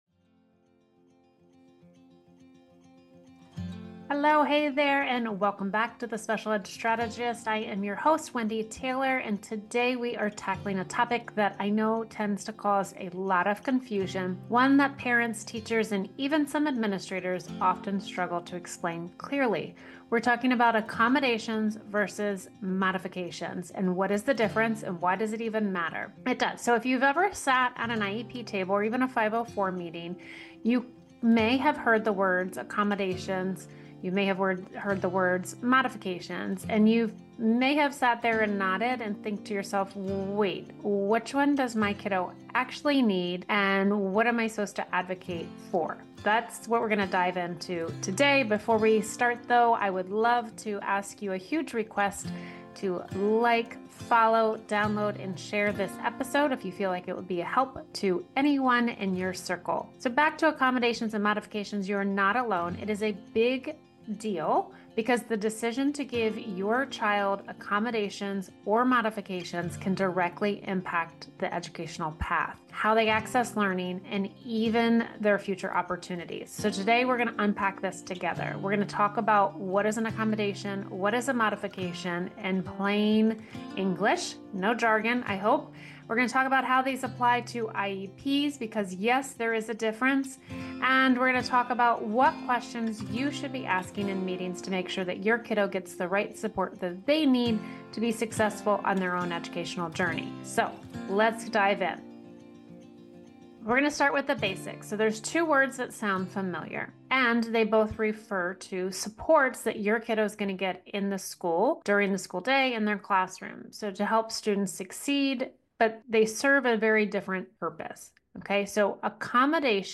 In this solo episode